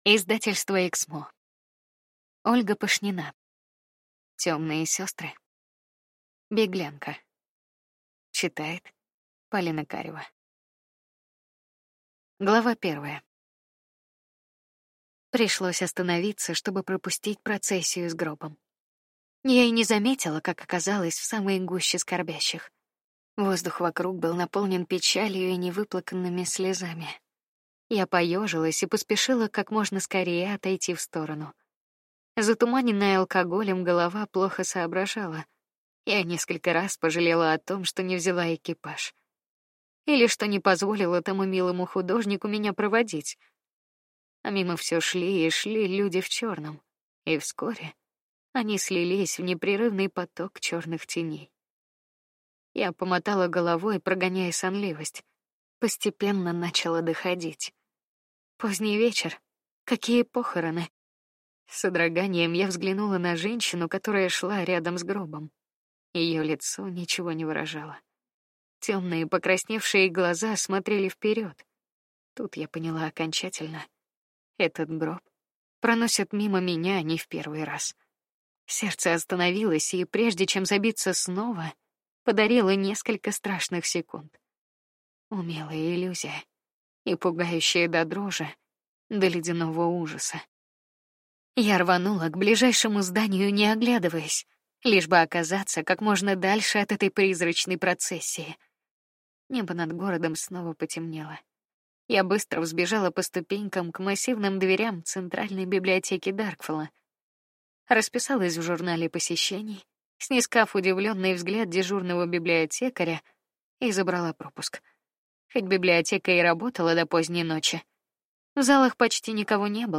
Аудиокнига Беглянка | Библиотека аудиокниг
Прослушать и бесплатно скачать фрагмент аудиокниги